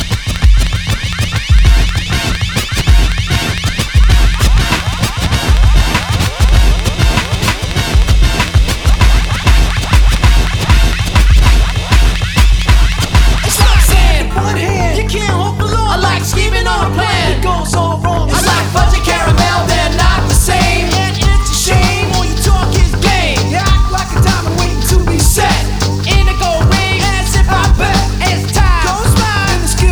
Жанр: Хип-Хоп / Рэп / Рок / Альтернатива